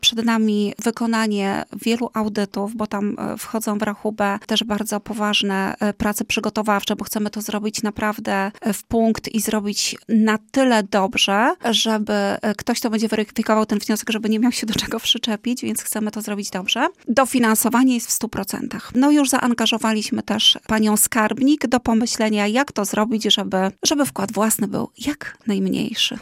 Wicestarosta przekonuje, że szanse na uzyskanie dofinansowania są.